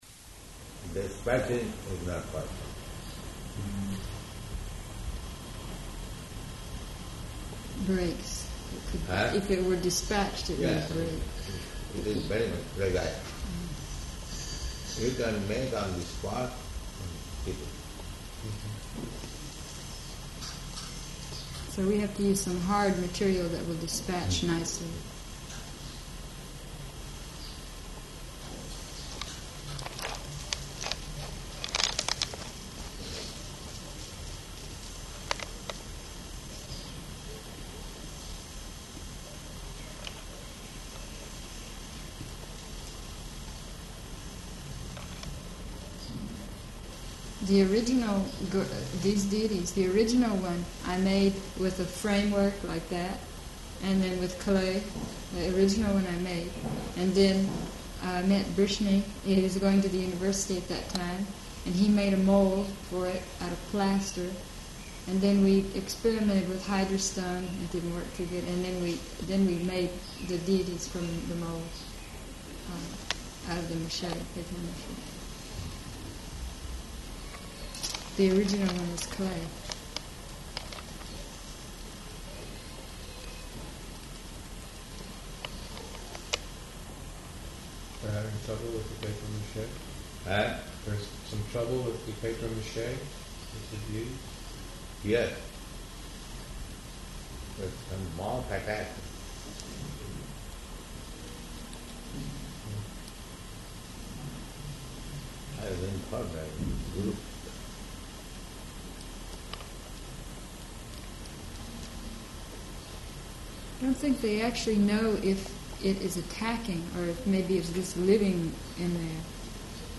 Room Conversation
Room Conversation --:-- --:-- Type: Conversation Dated: June 17th 1975 Location: Honolulu Audio file: 750617R1.HON.mp3 Prabhupāda: Dispatching is not possible.